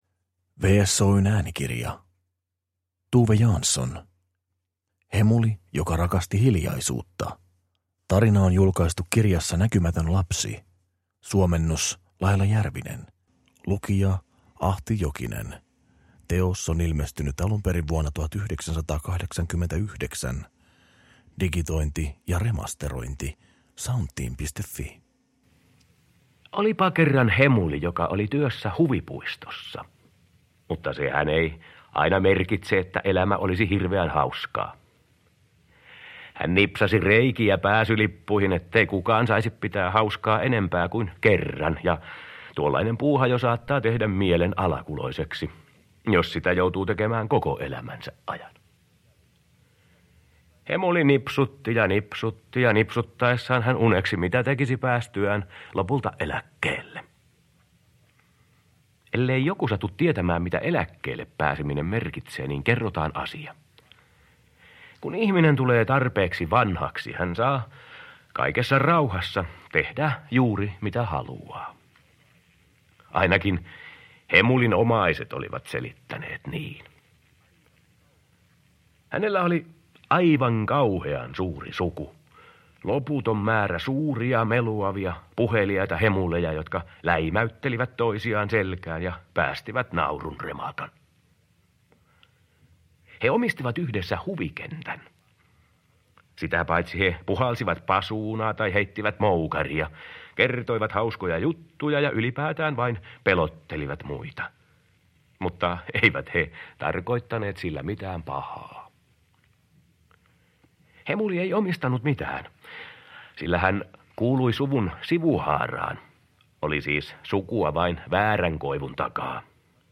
Hemuli joka rakasti hiljaisuutta – Ljudbok – Laddas ner